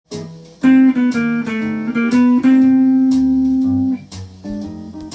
譜面通り弾いてみました
で使われているメロディーラインです。